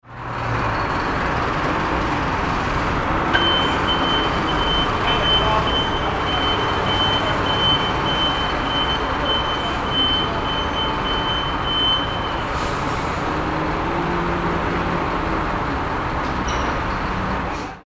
Urban Street Construction Sound Effect
Description: Urban street construction sound effect. A truck moves in reverse while its backup beeper sound plays clearly. This realistic construction sound effect adds authentic industrial and urban atmosphere to your video projects. Street sounds.
Urban-street-construction-sound-effect.mp3